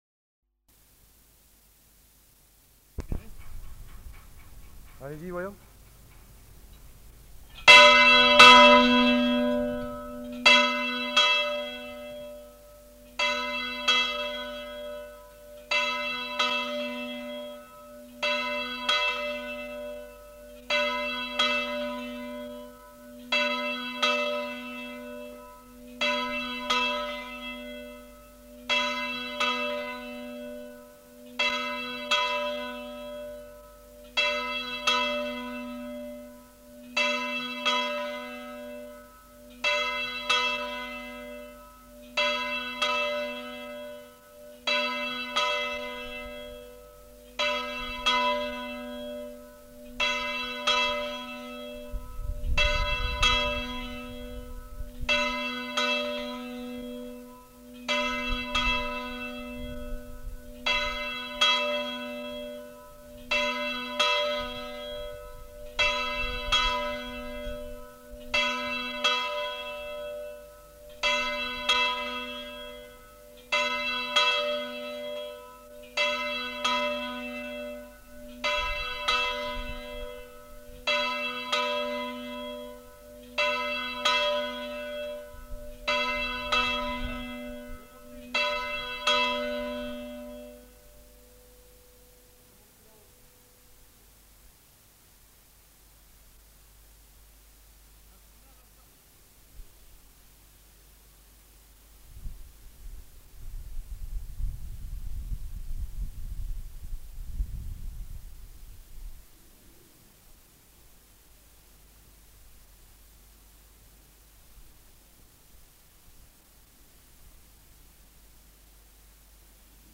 Sonnerie de l'angélus